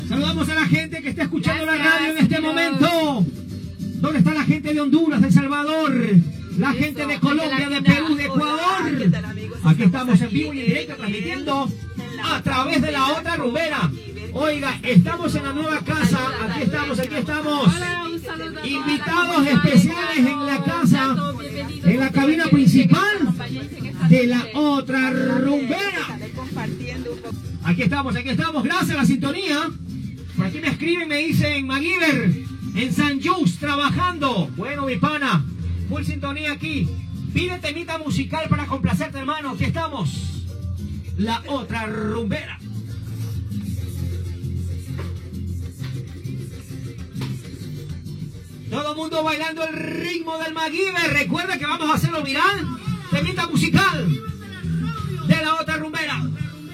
Identificació de l'emissora, missatge d'un oïdor
Entreteniment